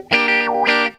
GTR 88 GM.wav